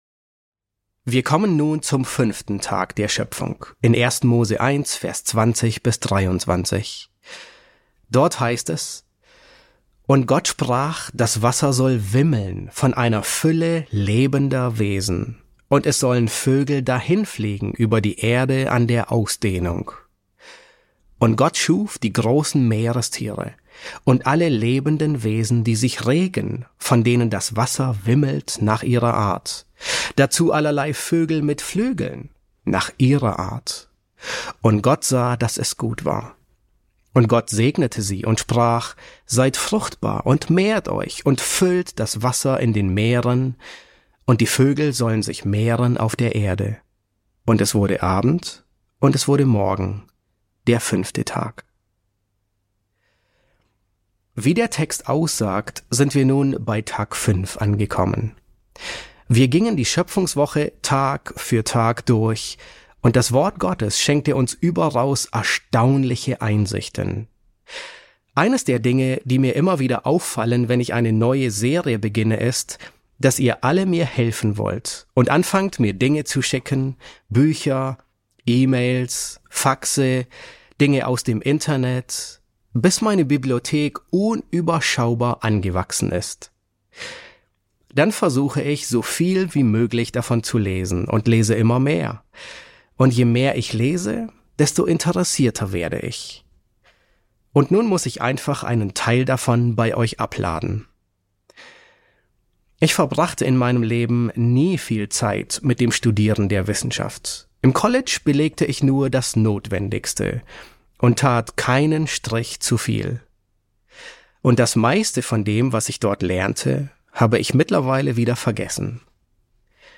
E9 S6 | Der 5. Schöpfungstag ~ John MacArthur Predigten auf Deutsch Podcast